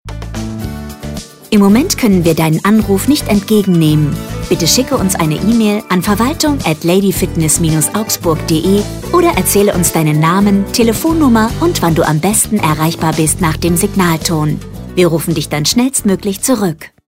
Sportliche Telefonansagen für Lady Fitness in Augsburg.
Ansage 5: